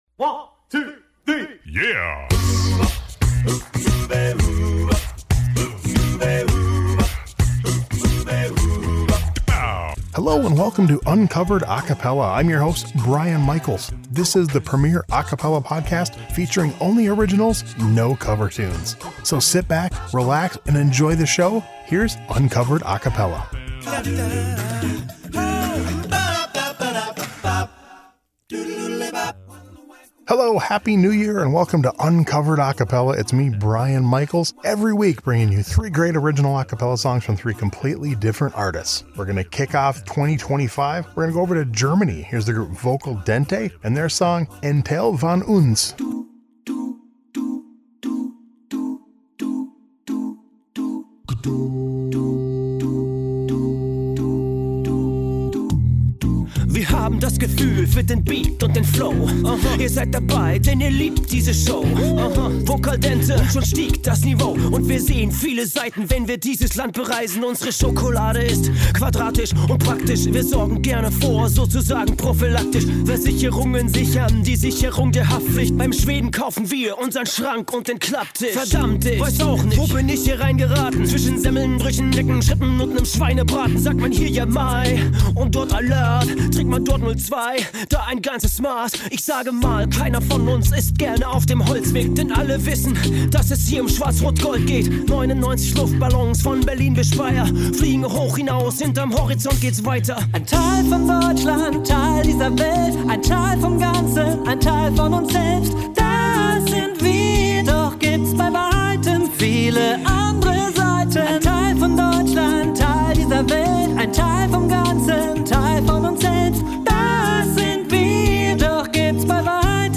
3 original a cappella songs every week!